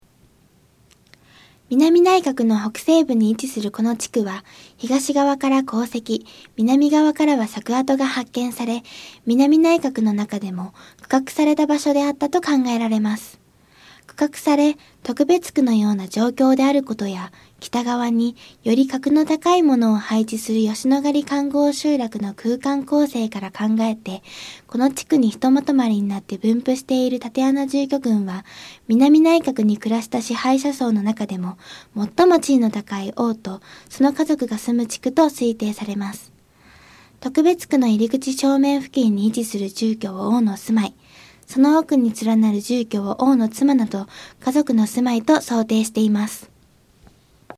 特別区の入り口正面付近に位置する住居を王の住まい、その奥に連なる住居を王の妻など家族の住まいと想定しています。 音声ガイド 前のページ 次のページ ケータイガイドトップへ (C)YOSHINOGARIHISTORICAL PARK